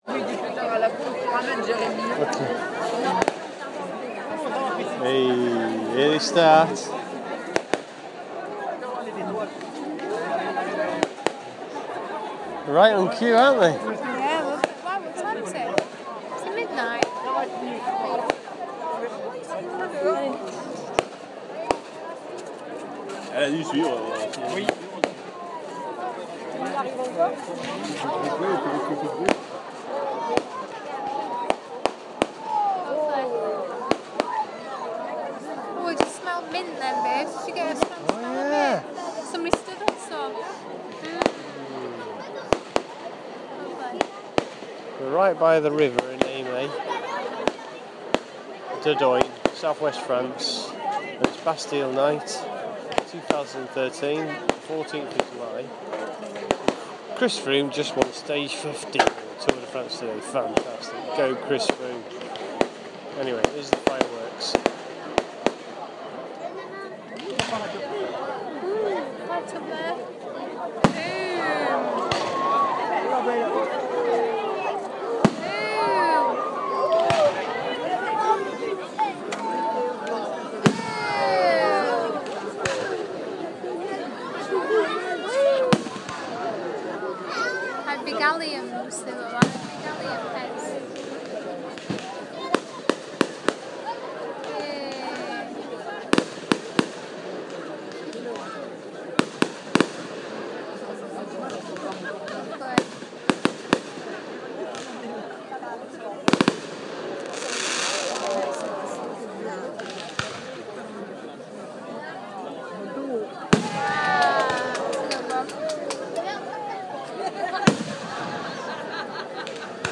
Fireworks at end of Eymet Bastille night